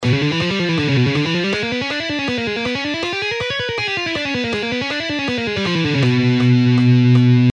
Diminished Scale:
Original Speed:
2.-Legato-Exercise-In-Diminished-Scale.mp3